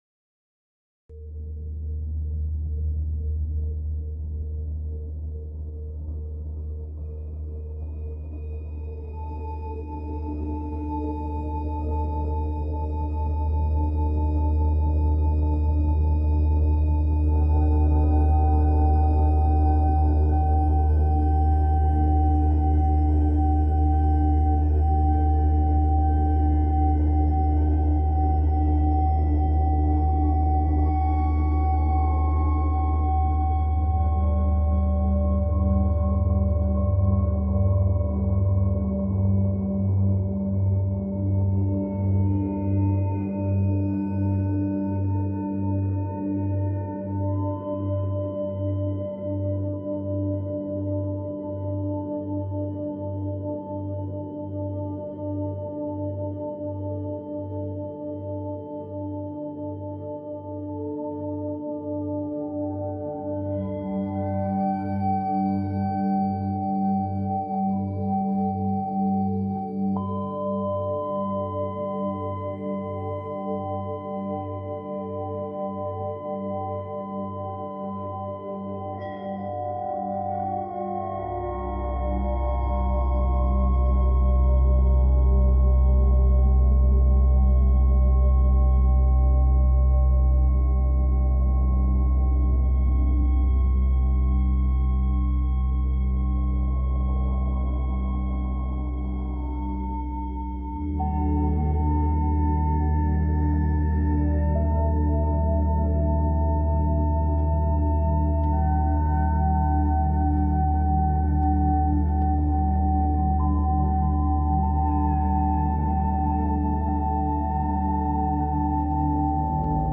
澄んだ響きが奥まで届く 💫 リラックス・瞑想・睡眠のお供に。